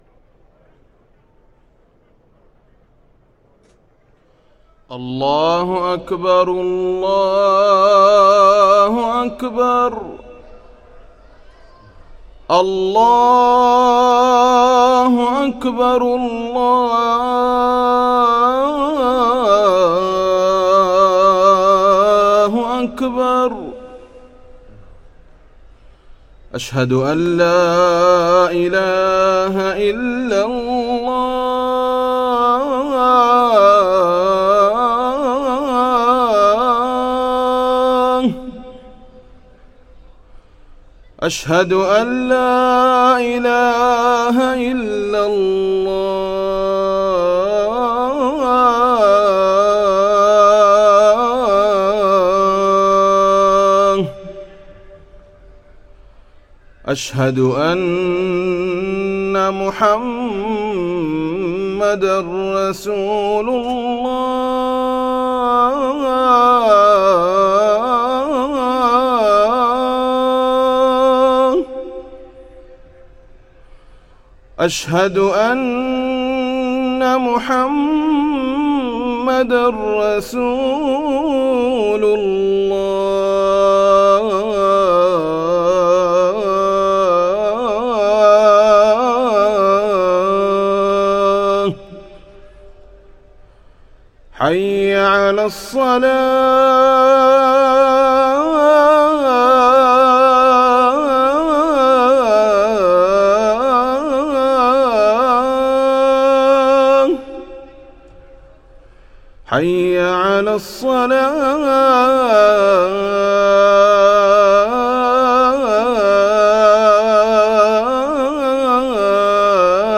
أذان الظهر للمؤذن سهيل حافظ السبت 18 جمادى الأولى 1445هـ > ١٤٤٥ 🕋 > ركن الأذان 🕋 > المزيد - تلاوات الحرمين